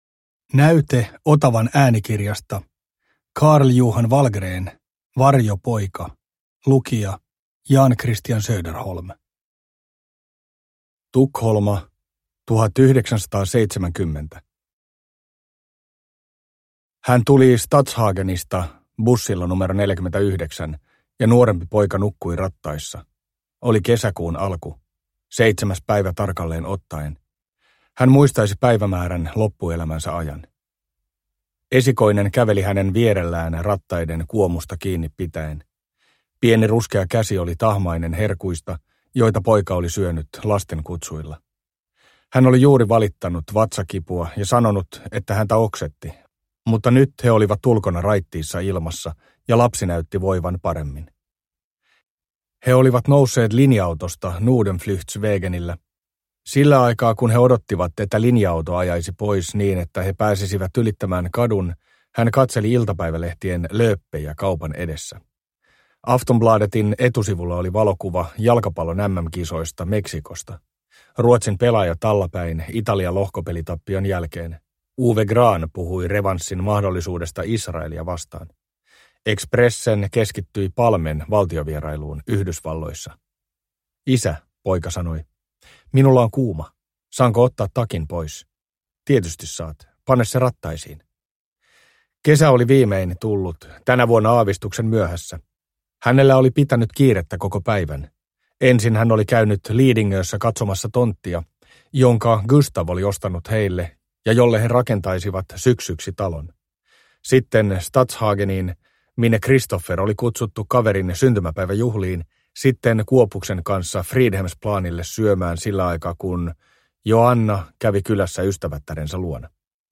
Varjopoika – Ljudbok – Laddas ner